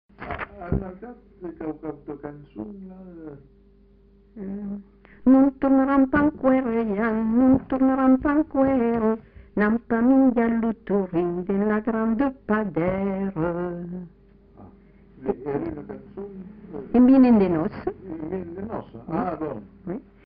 Aire culturelle : Bazadais
Lieu : Captieux
Genre : chant
Effectif : 1
Type de voix : voix de femme
Production du son : chanté
Danse : rondeau